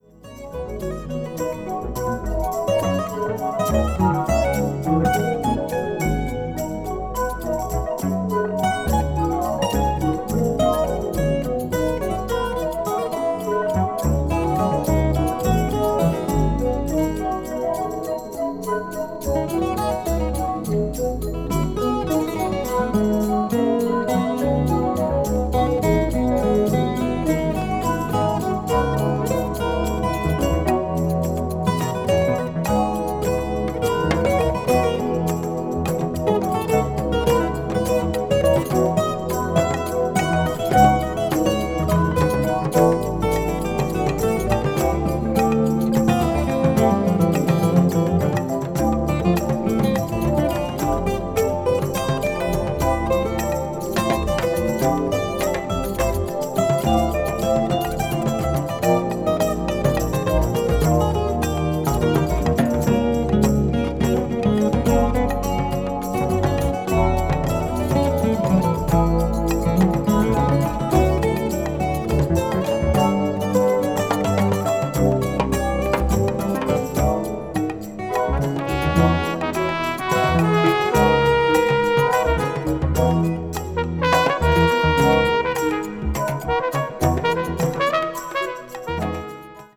media : EX/EX(わずかにチリノイズが入る箇所あり)
艶と張りのあるアコースティック・ギターと透明感溢れるピアノの音色が心に染み渡る、精神性の高い作品。美しい音の世界。